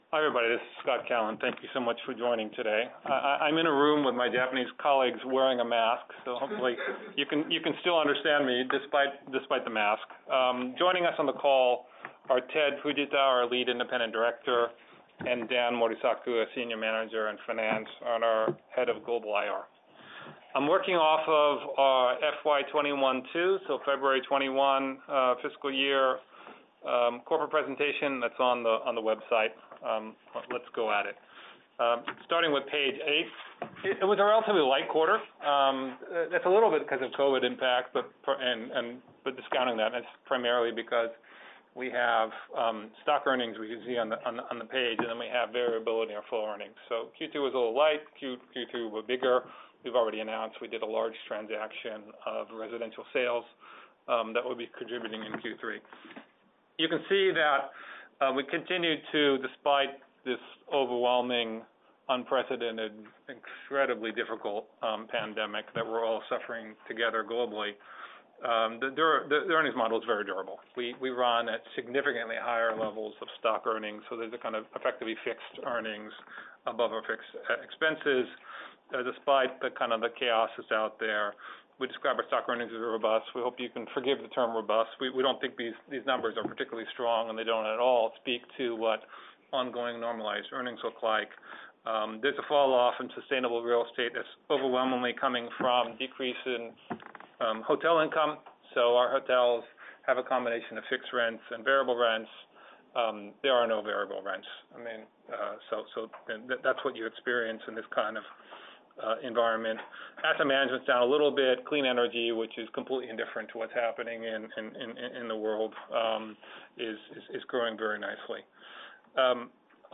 FY21/2 Q3 Earnings Call